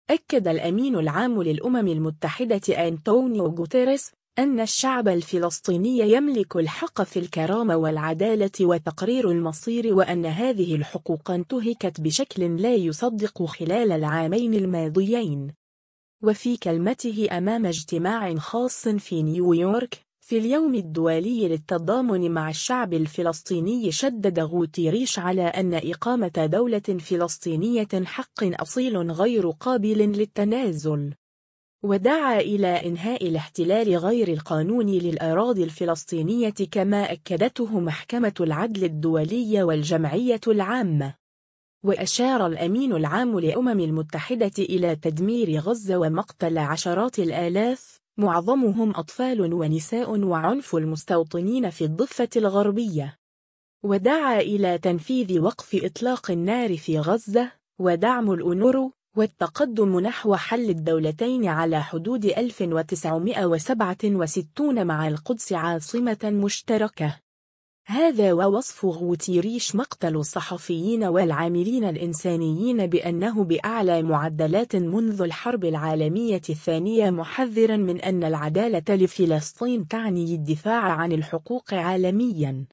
و في كلمته أمام اجتماع خاص في نيويورك، في اليوم الدولي للتضامن مع الشعب الفلسطيني شدّد غوتيريش على أن إقامة دولة فلسطينية حق أصيل غير قابل للتنازل.